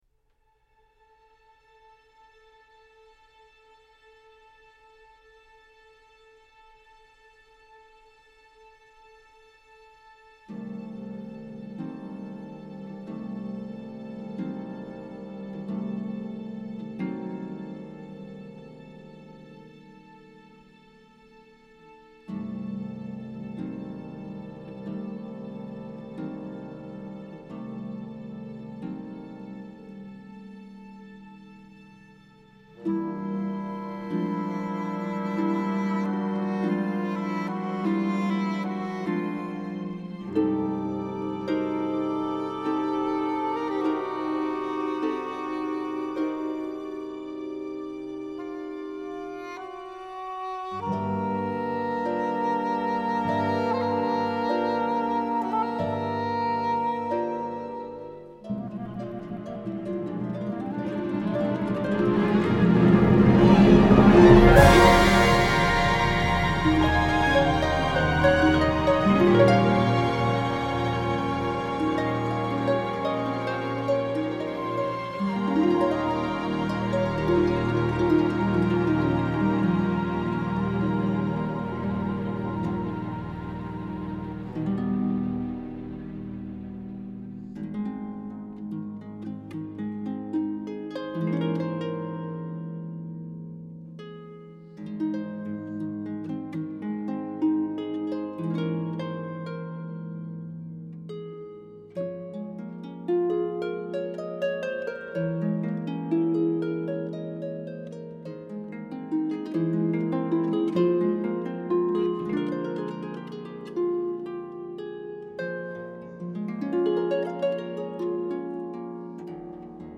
What does a musical blizzard sound like?